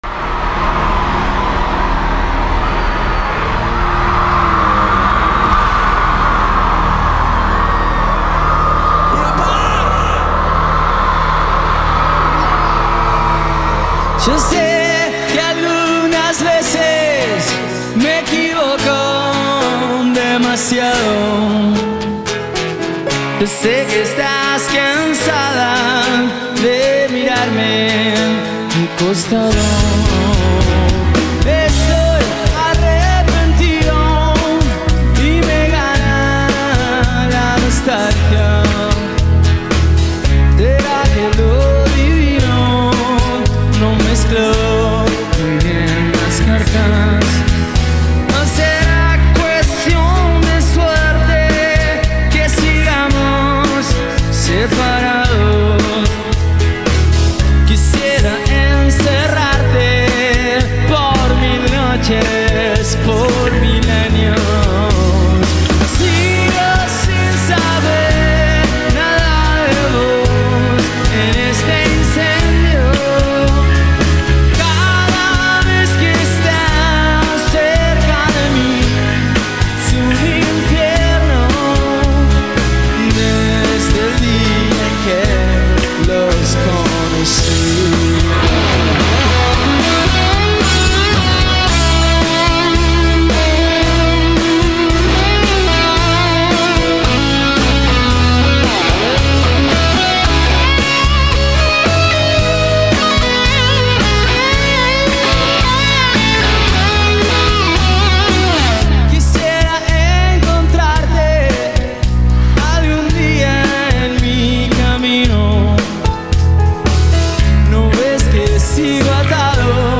Carpeta: Rock argentino mp3